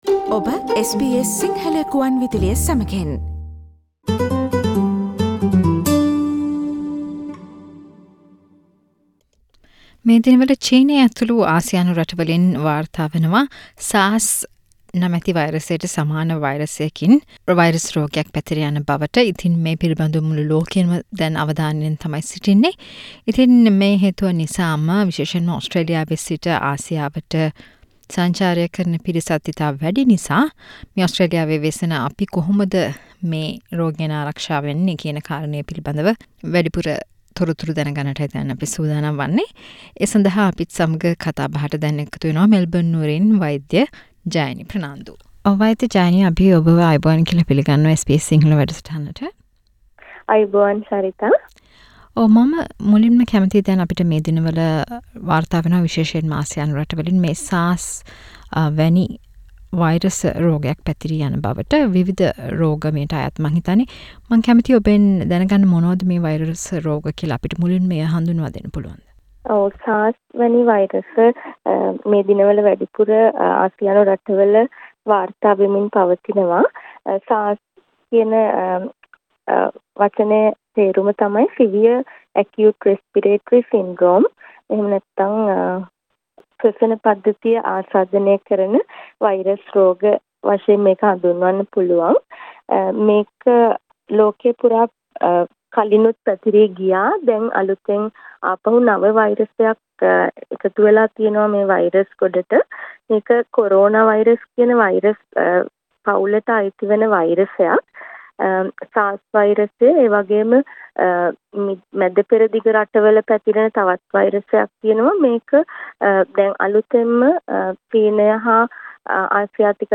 SBS සිංහල ගුවන් විදුලිය සිදුකල සාකච්චාව